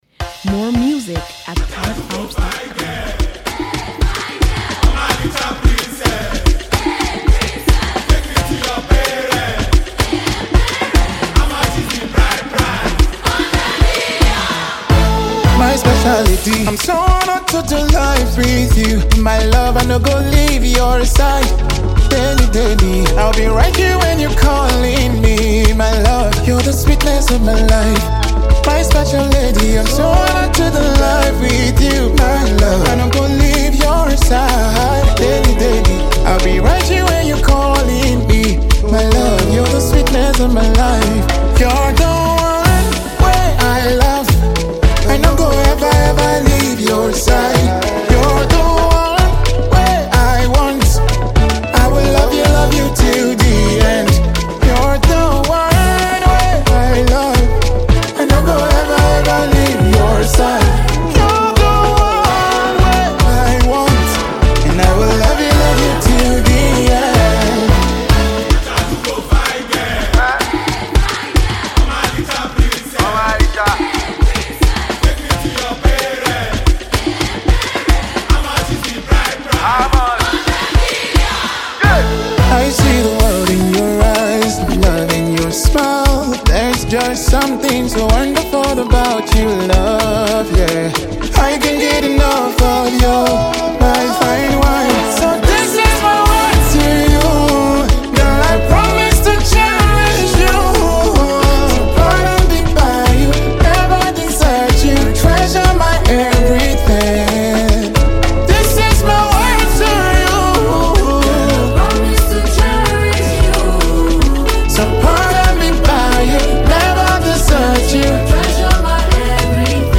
Renowned Nigerian gospel music sensation